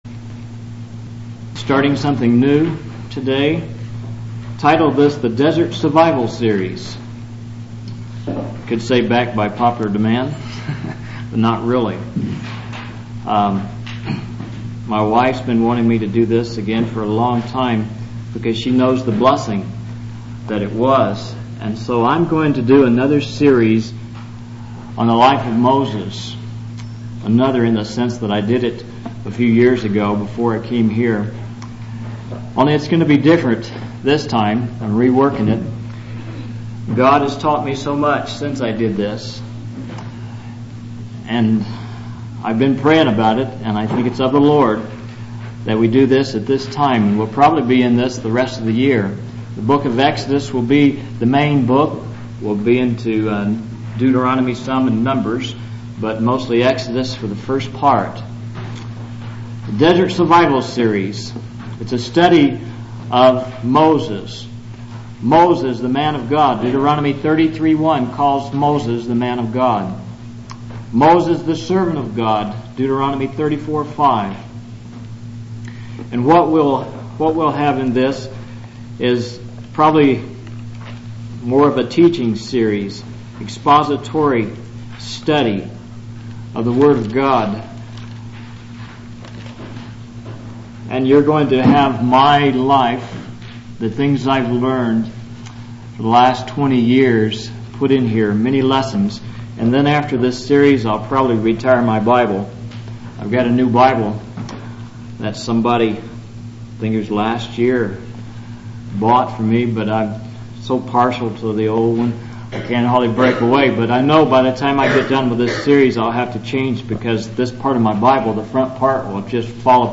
In this sermon, the preacher emphasizes the importance of discernment and doctrine in the face of deception. He uses the example of Moses' life to illustrate the three stages of the Christian journey: the palace, the desert, and leading others to the promised land.